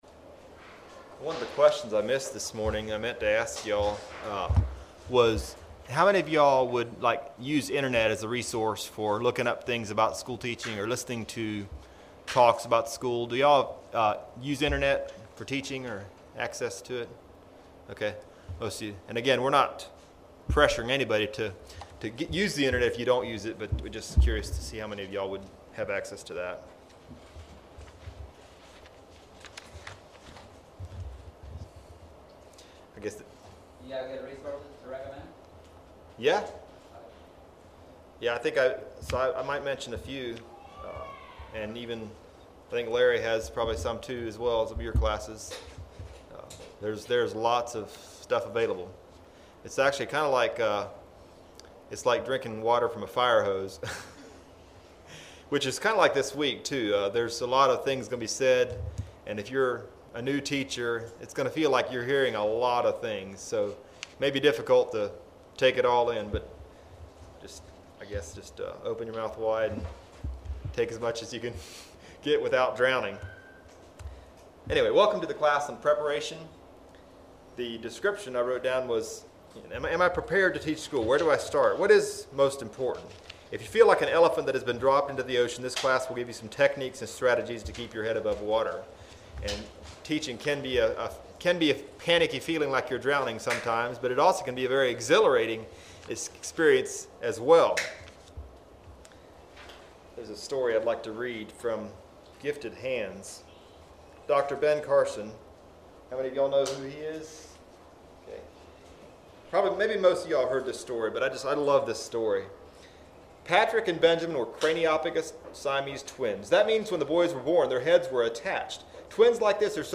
2025 Midwest Teachers Week 2025 Recordings Preparation Audio 00:00